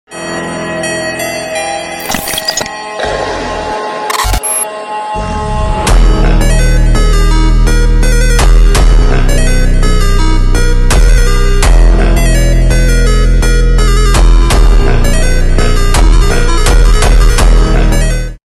828 beim Mist streuen und sound effects free download